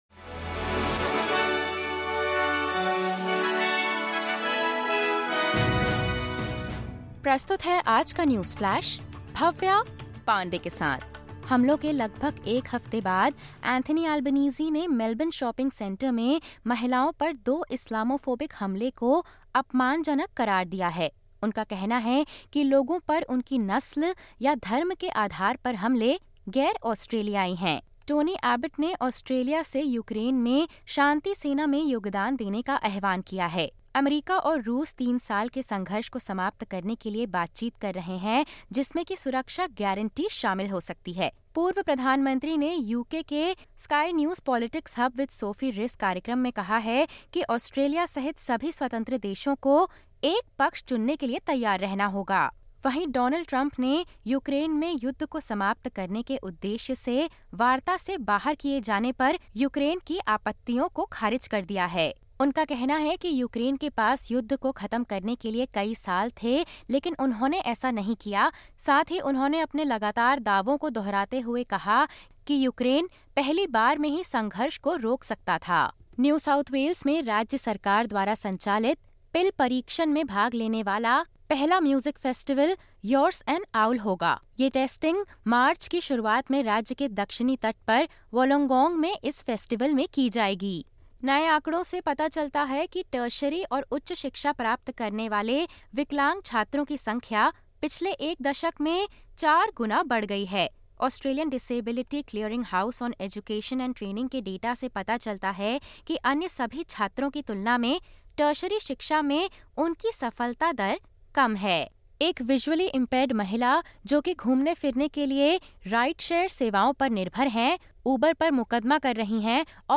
सुनें ऑस्ट्रेलिया और भारत से 19/02/2025 की प्रमुख खबरें।